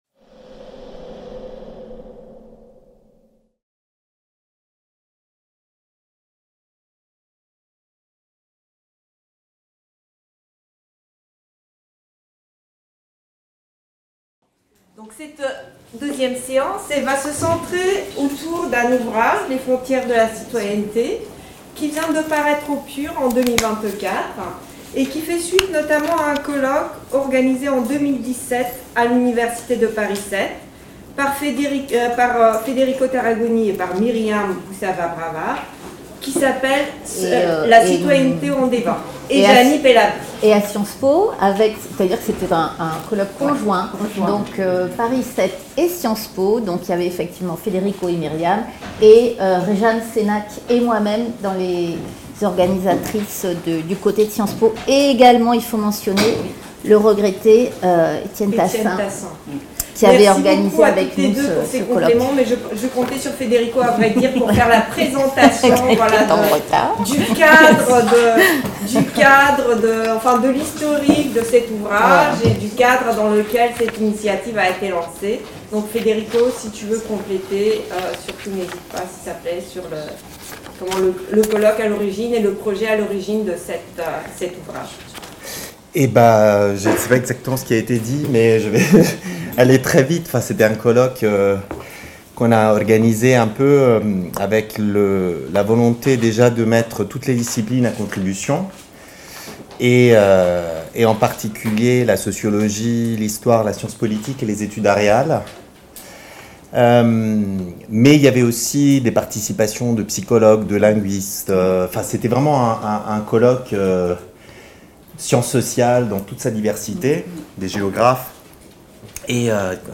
Séminaire des invités Master Sociologie